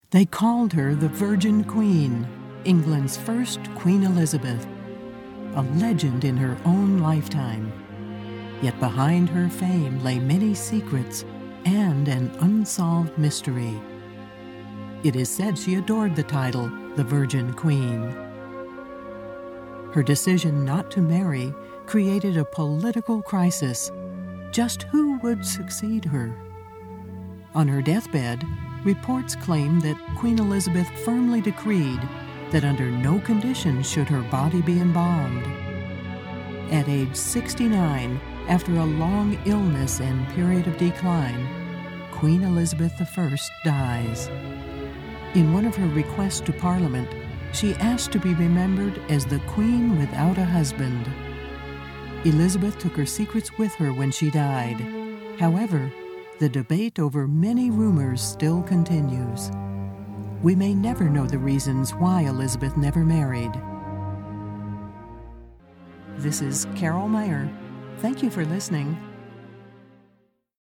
Sennheiser 416 mic, Blue Robbie preamp, Mogami cabling, ProTools 8.04 with various additional plug-ins, MBox mini, Adobe Audition, music and sound fx library.
Smooth, classy, believable.
Sprechprobe: Werbung (Muttersprache):